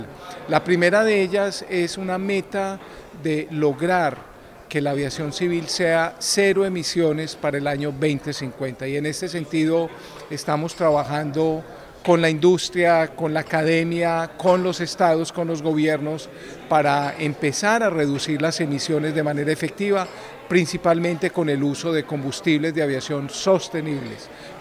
En entrevista con el Sr. Juan Carlos Salazar, Secretario General de la OACI, conocimos sobre las estrategias para hacer viables las prioridades del sector a nivel global con el apoyo conjunto de los estados, sobre todo los de la región de Norteamérica, Centroamérica y el Caribe.
corte-1-entrevista-aeronatica.mp3